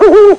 1 channel
OWL.mp3